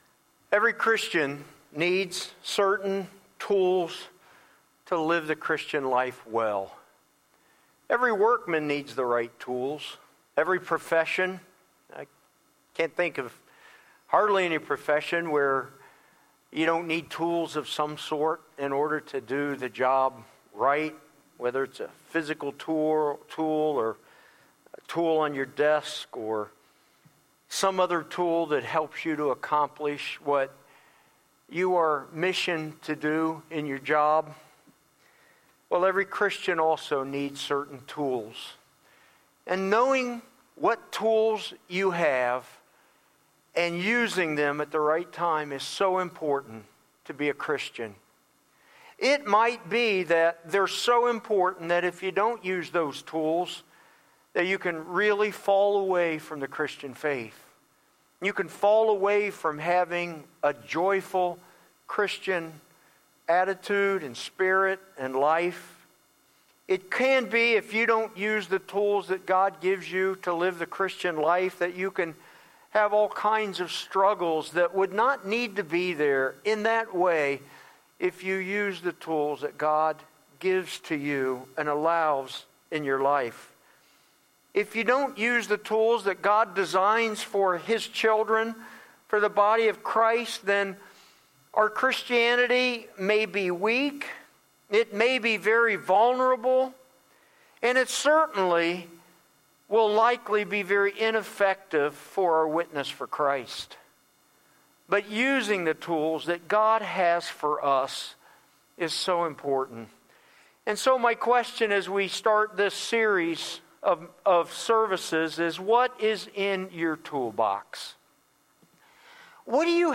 1-12-20am-Sermon.mp3